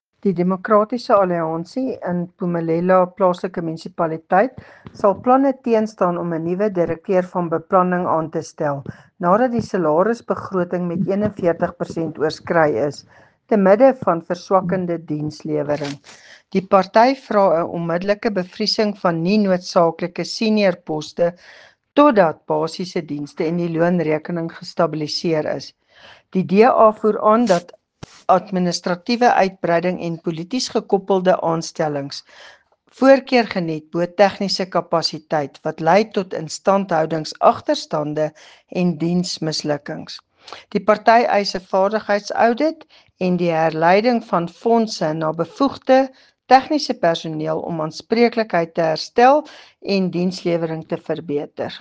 Afrikaans soundbites by Cllr Doreen Wessels and